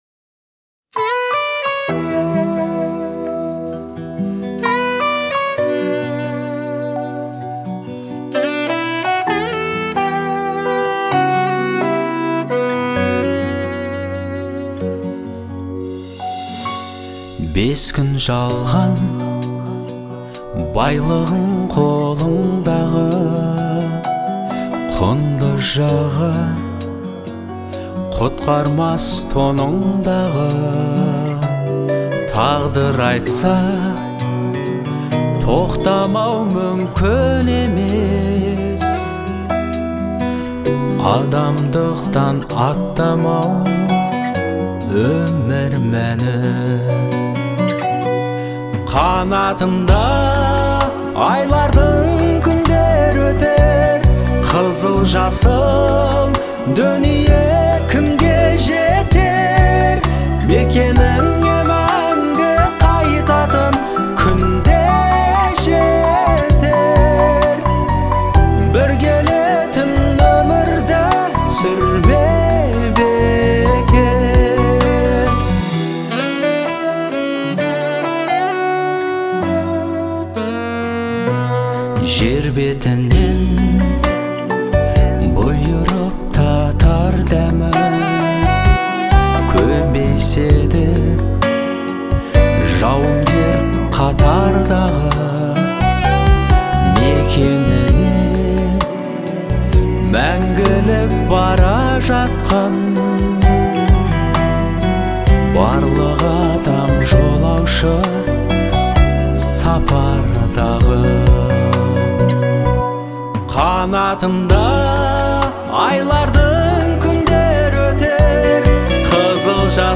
относится к жанру поп с элементами этнической музыки